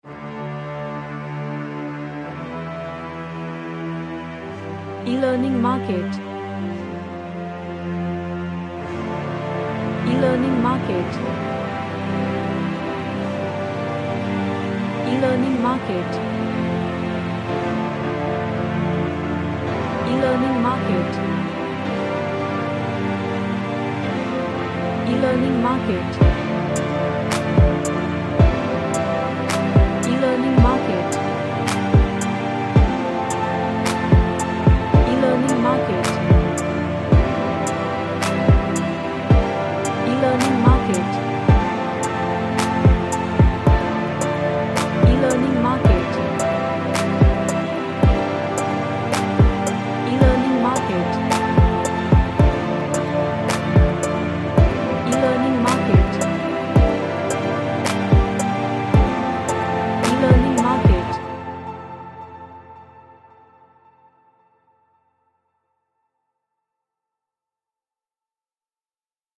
A sad melodical chill house track
Sad / Nostalgic